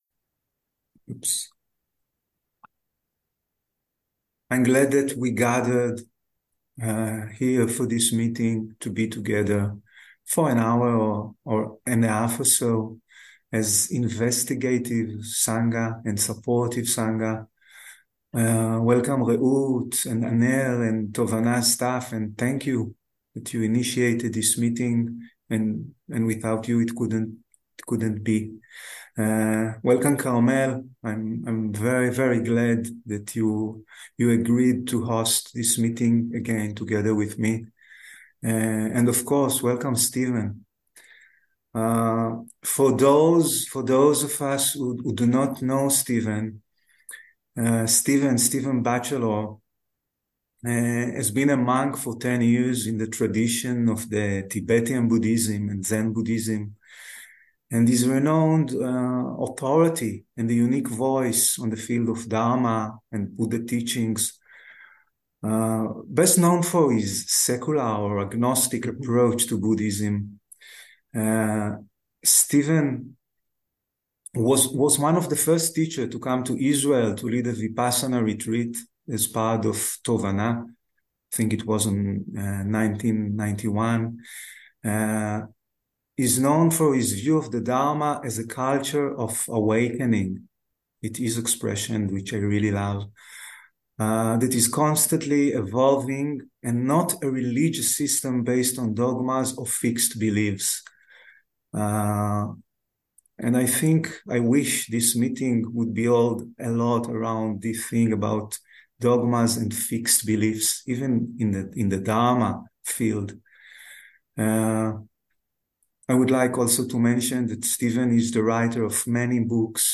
סטיבן בצ'לור סוג ההקלטה: שיחות דהרמה שפת ההקלטה: אנגלית מידע נוסף אודות ההקלטה: שם השיחה: דהרמה ברגעים של אתגרים קיומיים וטרגדיה - Dharma in times of tragedy and existential challenges שם המורה: סטיבן בצ'לור - Stephen Batchelor שם הריטריט: מרחב בטוח - Safe Space שנה: 2023 הקלטות נוספות: מרחב בטוח בזום